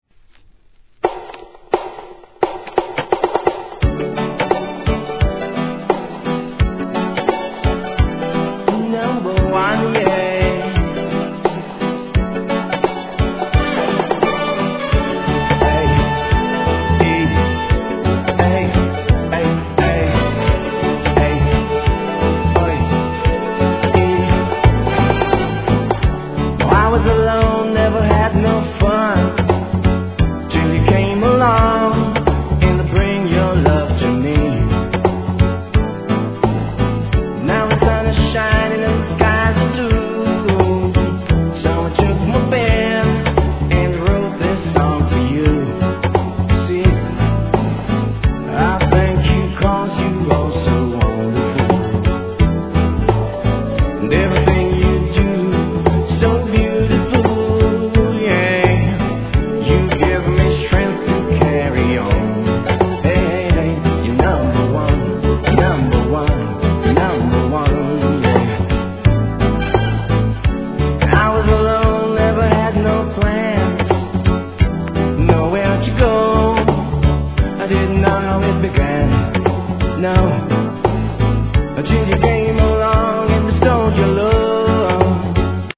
REGGAE
洗練された綺麗なトラックでベテランのヴォーカル!!